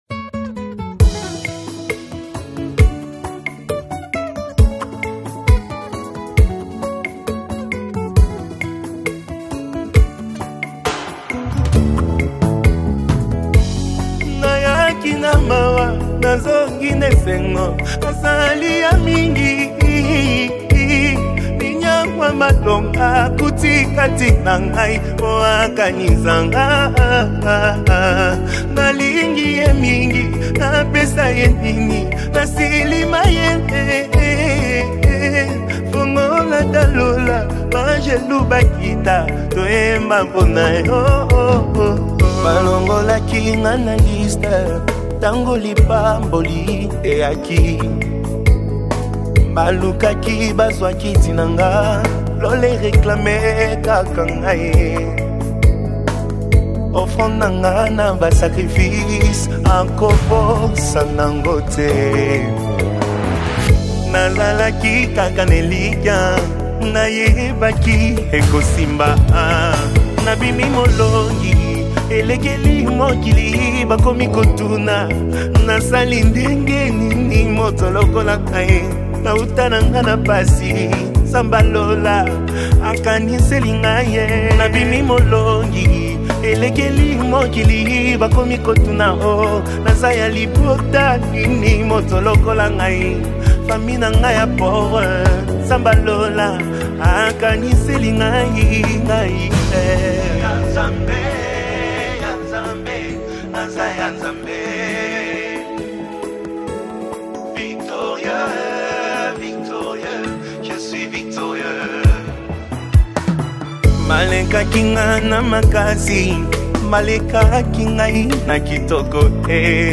Congo Gospel Music